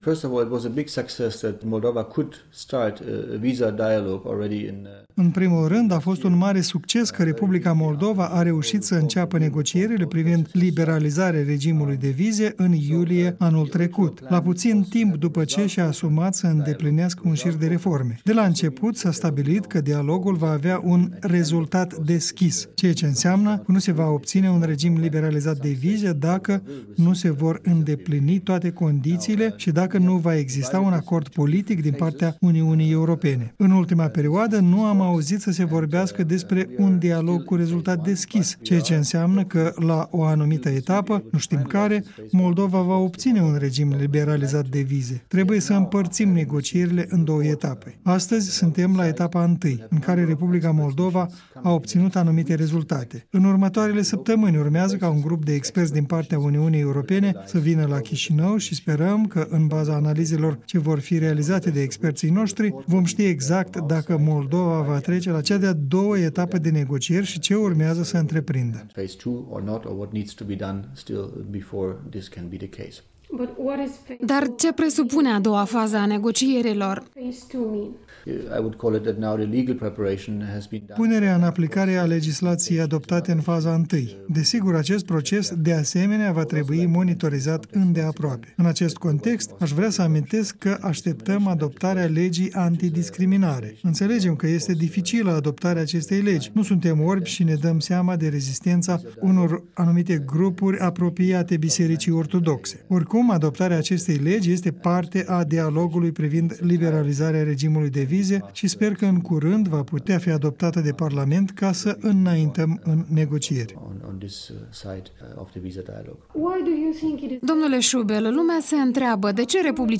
Un interviu cu Dirk Schuebel